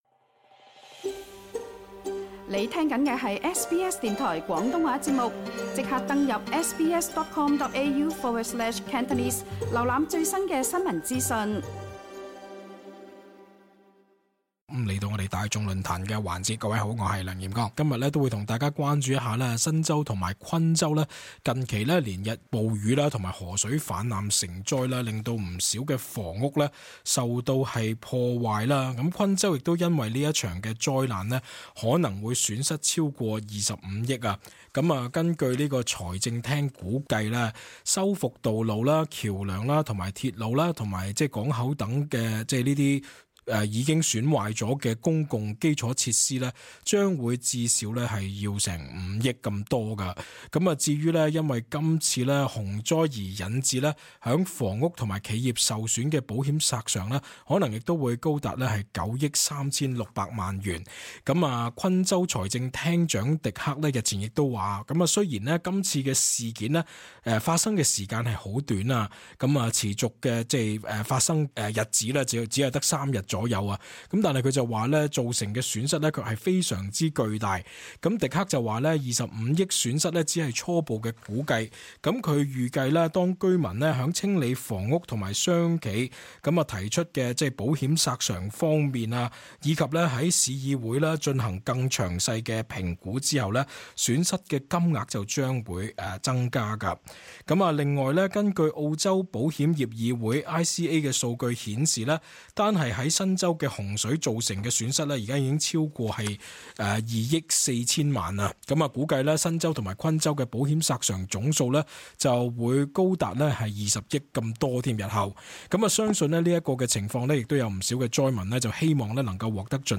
cantonese_-_talkback_-_march_10_-_final_flooding.mp3